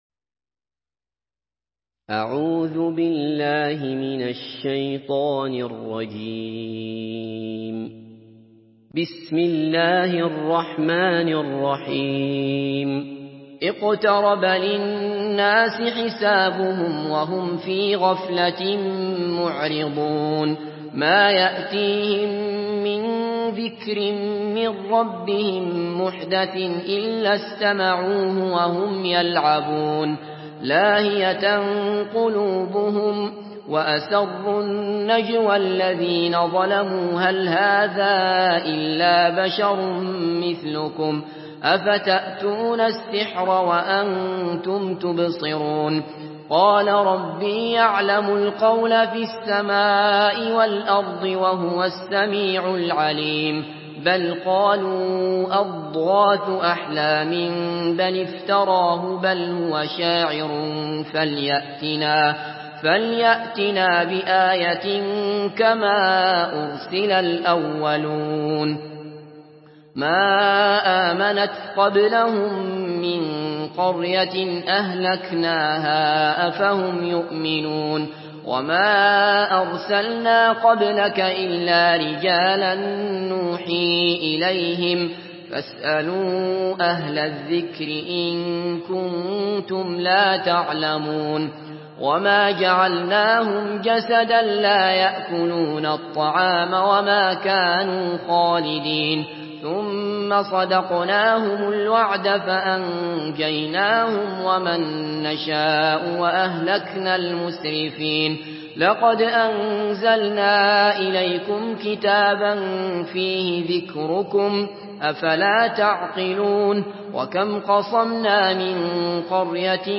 Surah الأنبياء MP3 by عبد الله بصفر in حفص عن عاصم narration.
مرتل حفص عن عاصم